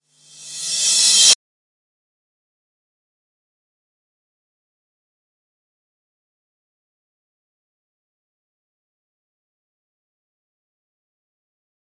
反转镲片 " Rev Cymb 14
Tag: 回声 金属 FX 反向